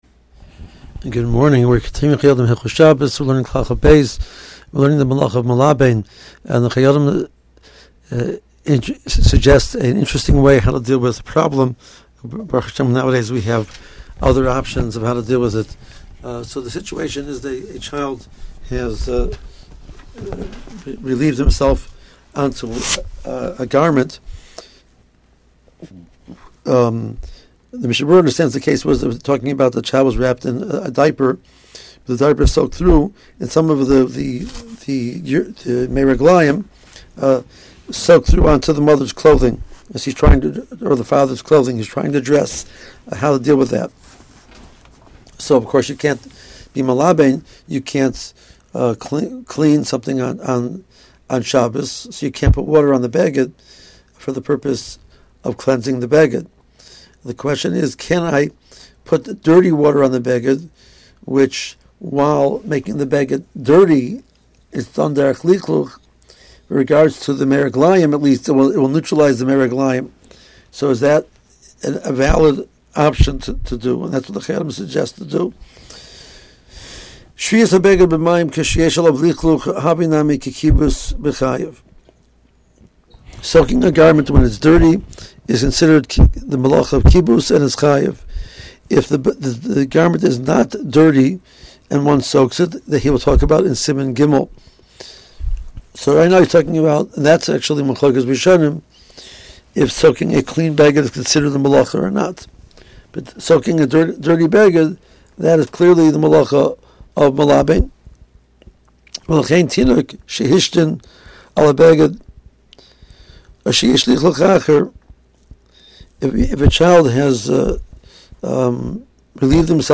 AUDIO SHIUR